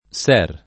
ser [ S$ r ]